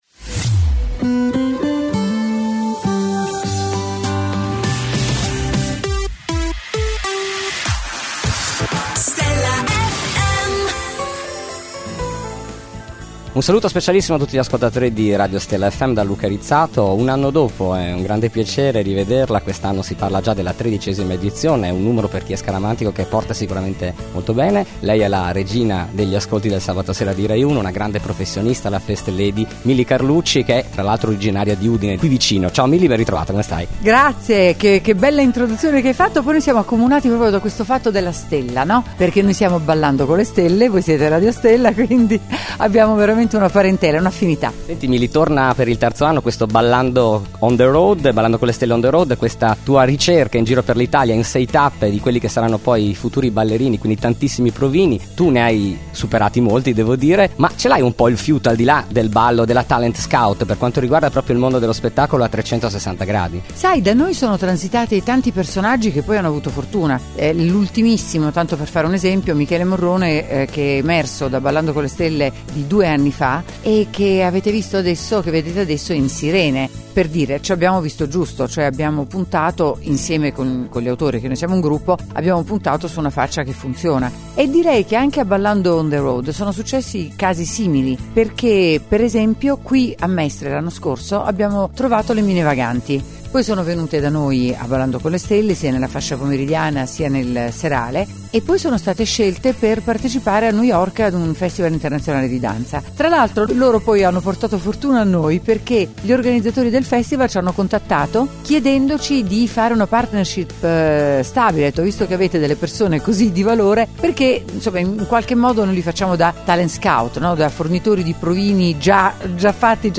Intervista esclusiva dell’inviato per Stella Fm a Milly Carlucci.